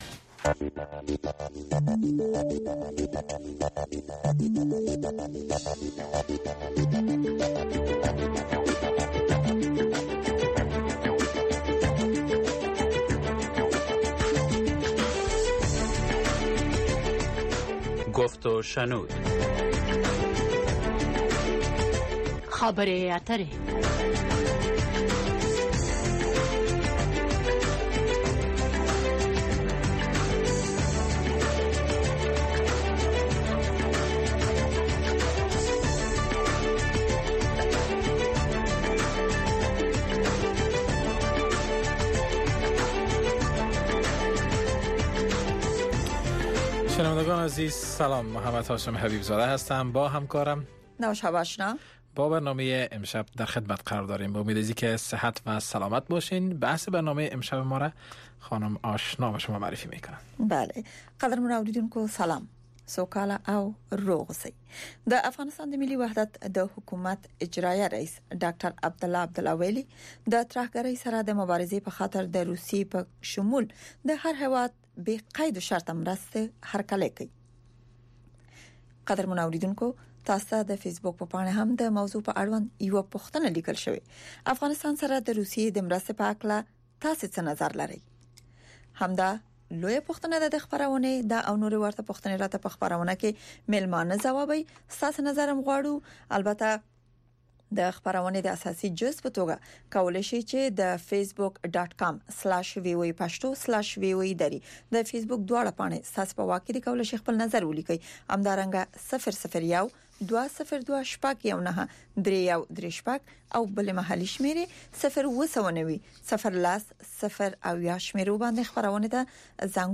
گفت و شنود - خبرې اترې، بحث رادیویی در ساعت ۰۸:۰۰ شب به وقت افغانستان به زبان های دری و پشتو است. در این برنامه، موضوعات مهم خبری هفته با حضور تحلیلگران و مقام های حکومت افغانستان به بحث گرفته می شود.